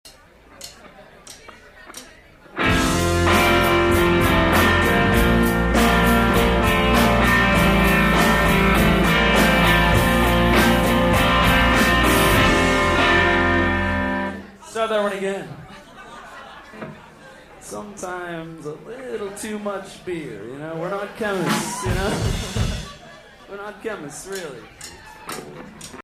schuba's chicago september 2000